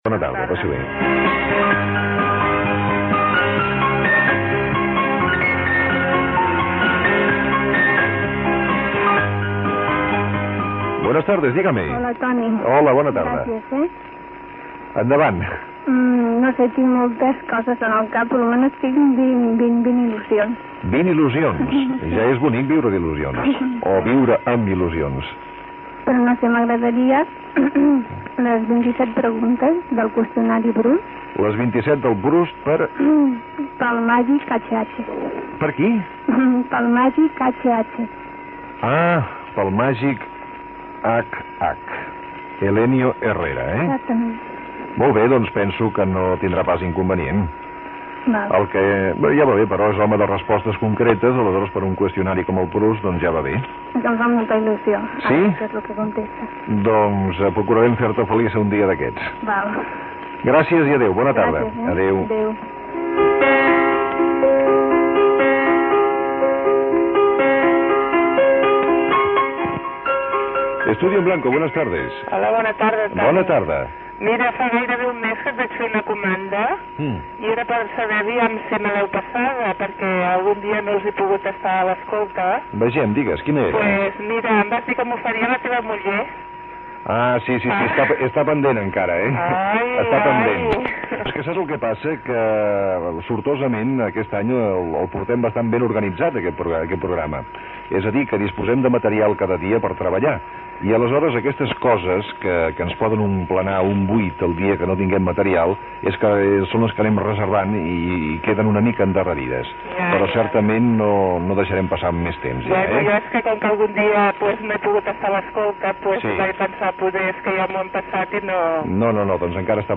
Trucades de l'audiència fent les seves demandes al programa, hora, identificació del programa i de l'emissora